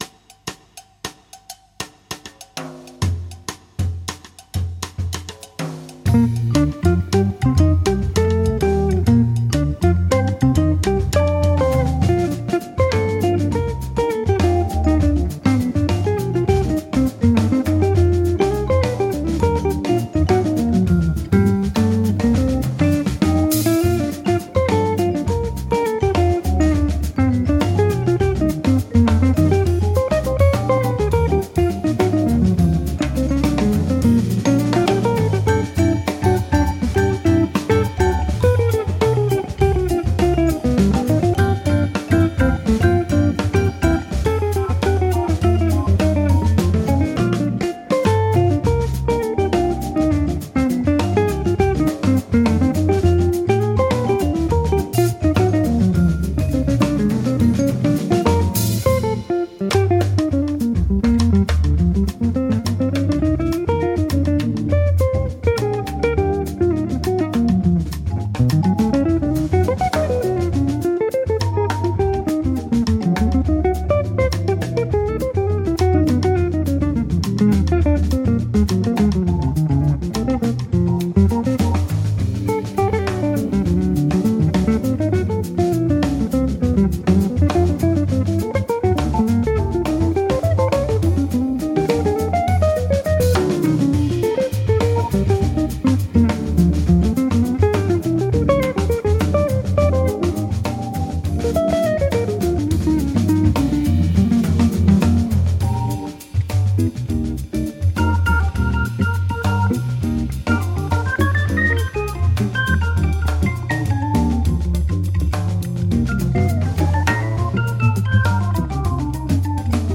jazz, caraïbes, funky groove ou bop
avec orgue et vibraphone
guitare
orgue
batterie
vibraphone.